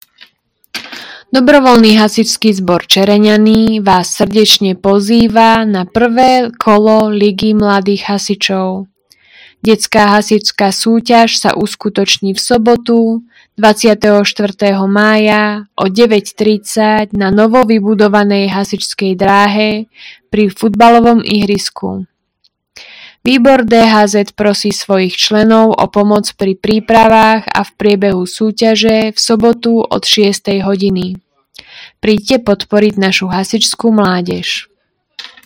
Hlásenie obecného rozhlasu – 1. kolo ligy mladých hasičov